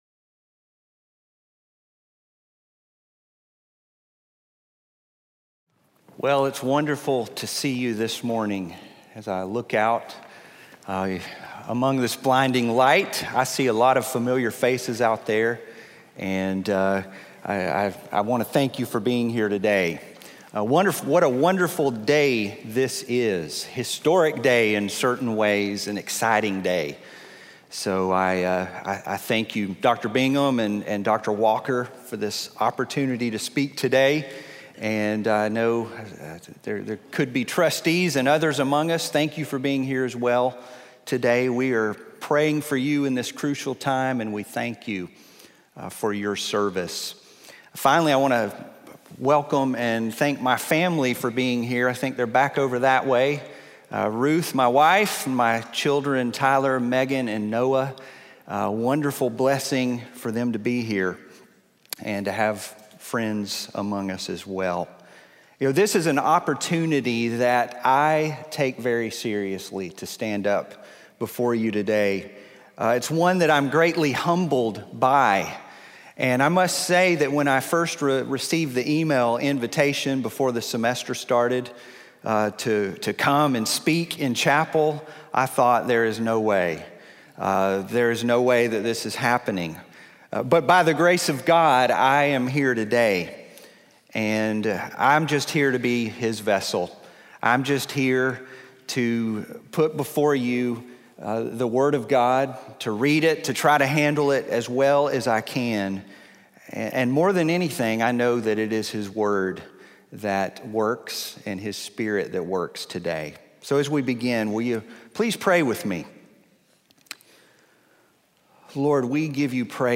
in SWBTS Chapel